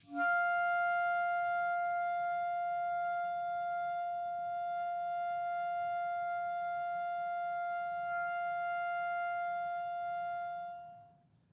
Clarinet
DCClar_susLong_F4_v1_rr1_sum.wav